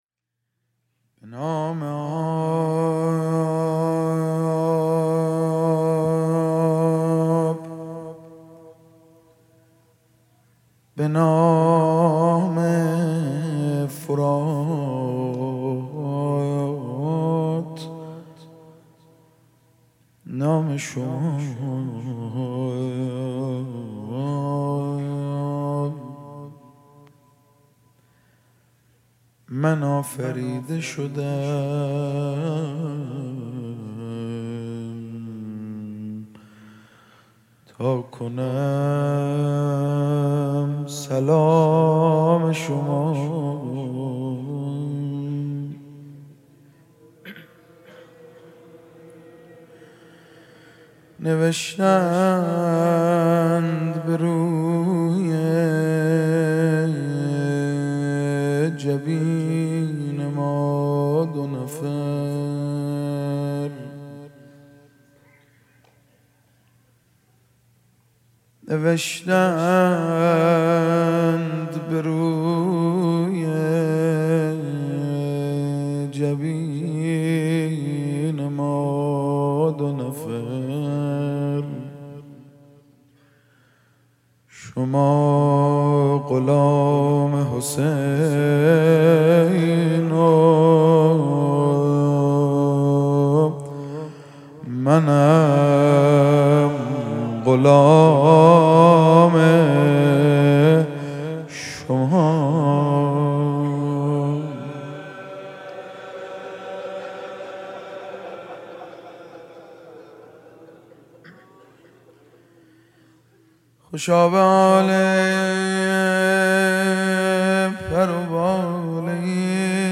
مداح
مناسبت : شب دوم محرم